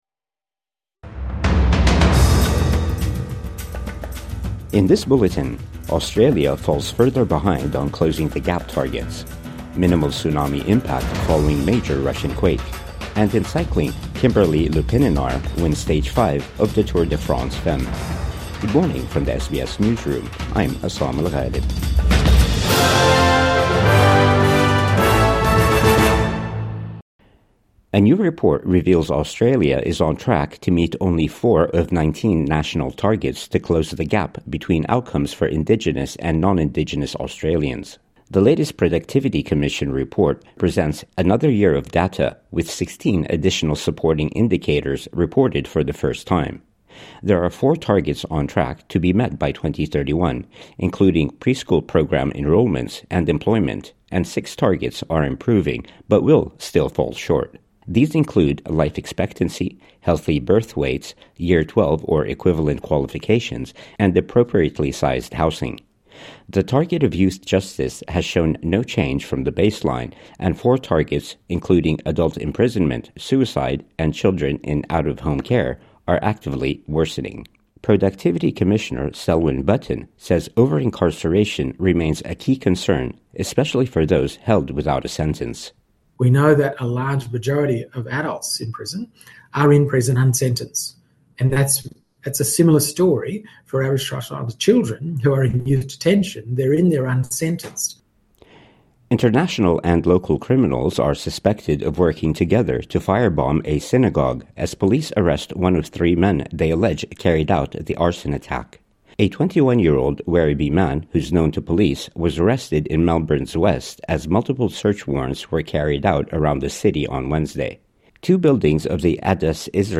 Australia falls further behind on Closing the Gap targets | Morning News Bulletin 31 July 2025